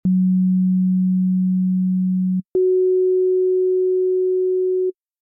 You should hear a very simple sine wave tone.
This simple sine tone is going to be the base note for our sound, or the fundamental frequency.